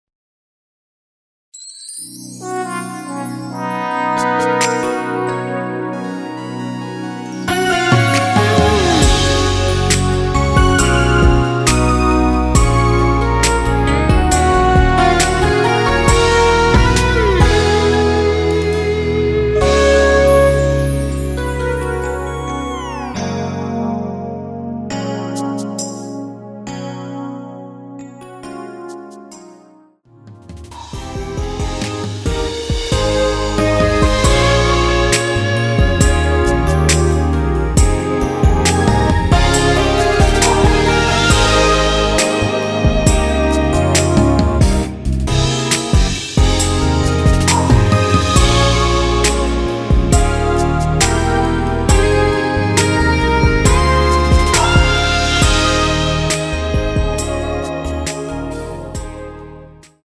원키에서(-1)내린 MR입니다.
F#
앞부분30초, 뒷부분30초씩 편집해서 올려 드리고 있습니다.